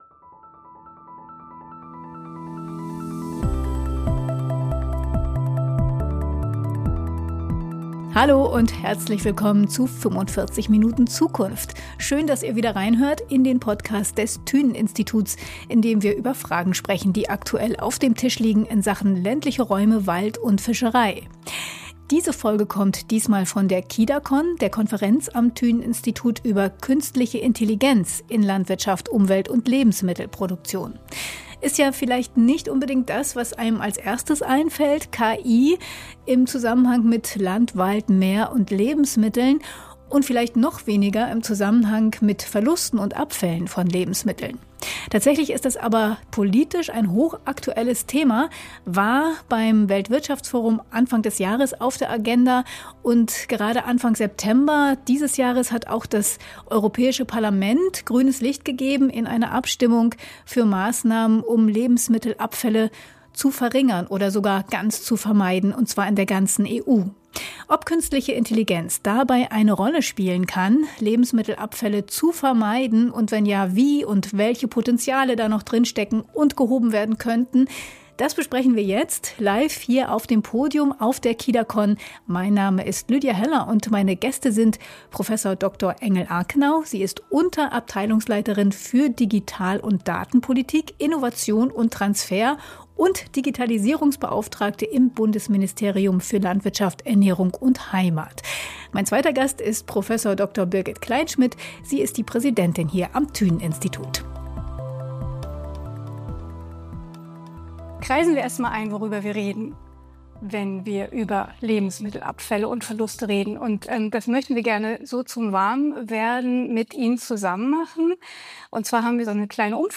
Lebensmittelabfälle belasten Umwelt, Wirtschaft und Gesellschaft. Im Gespräch mit unseren Gästinnen klären wir, wo die Abfälle entstehen, wie Künstliche Intelligenz sie eindämmen kann, und wo Politik und Forschung ansetzen sollten.